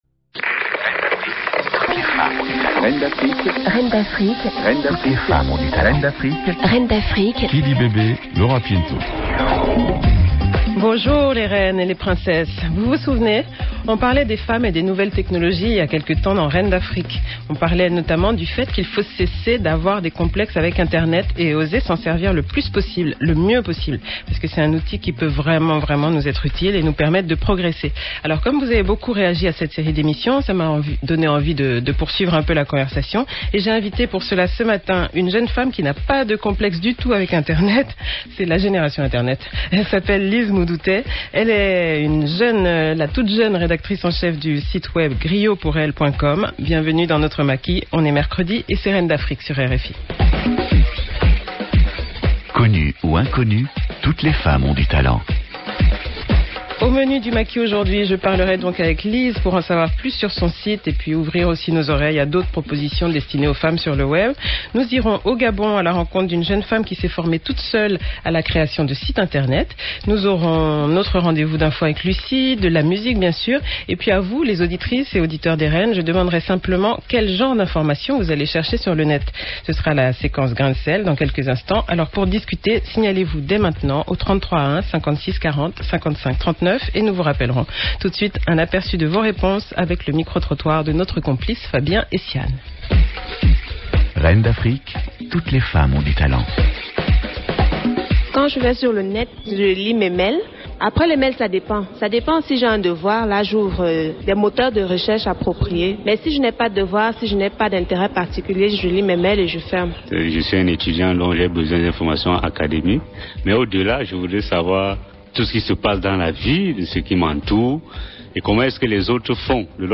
Ecoutez l'enregistrement de l'�mission en deux parties